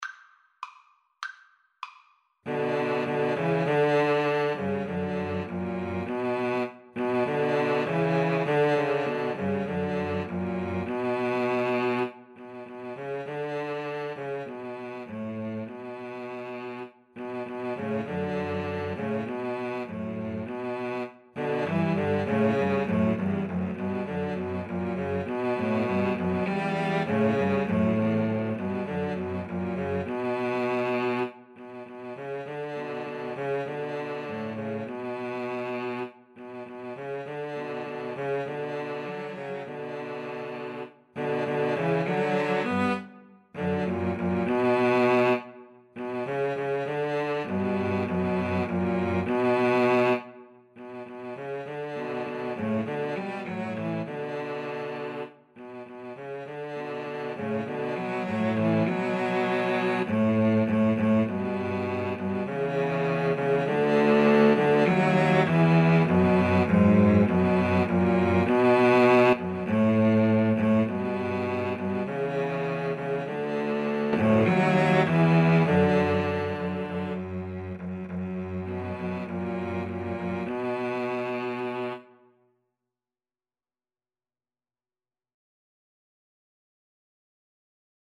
Free Sheet music for Cello Trio
Moderato
B minor (Sounding Pitch) (View more B minor Music for Cello Trio )